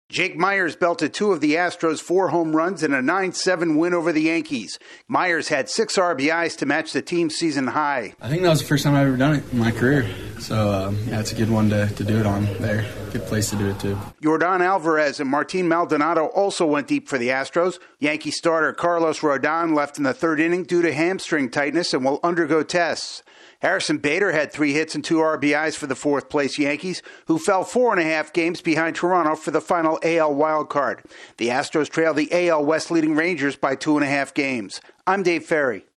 The Astros get a six-RBI performance and gain a split of their four-game series with the Yankees. AP correspondent